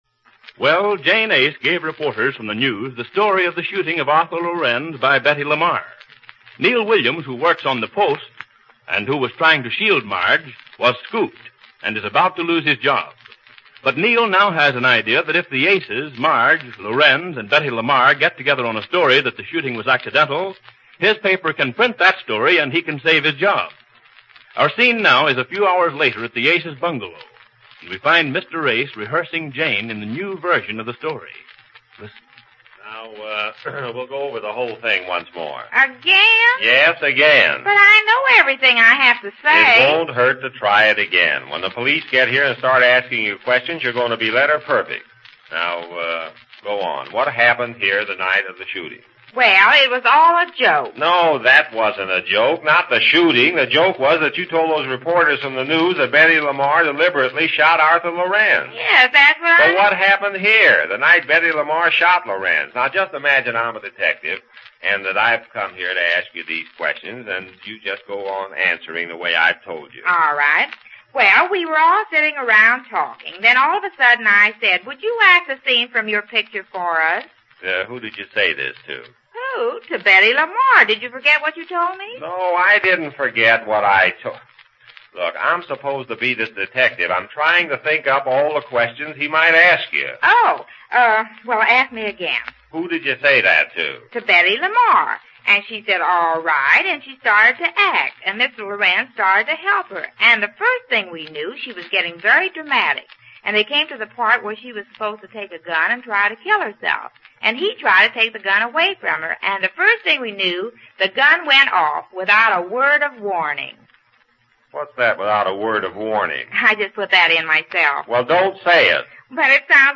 Easy Aces Radio Program, Starring Goodman Aiskowitz and Jane Epstein-Aiskowitz